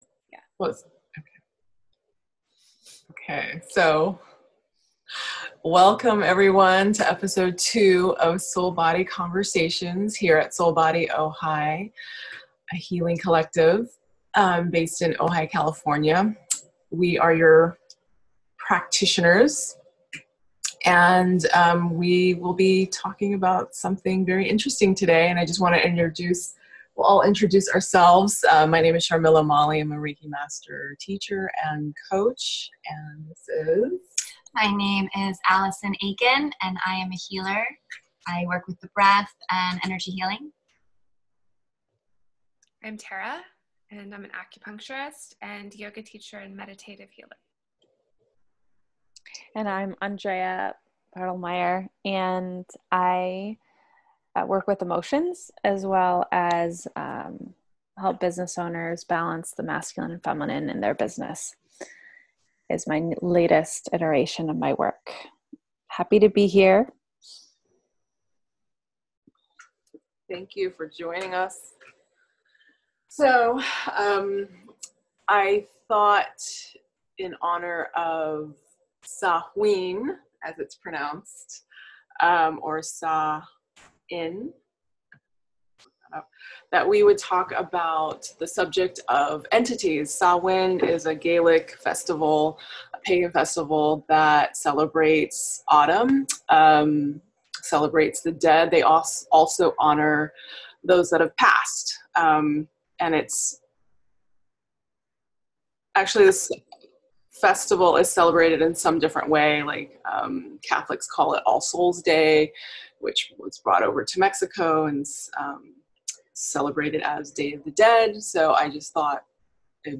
As we prepare to celebrate Samhain, All Souls Day, Halloween and Dia de los Muertos, the Soul Body Team gathers to discuss their personal and professional experiences with entities and ideas about soul clearing and releasing negative energies to return to love. This conversation is just under an hour long.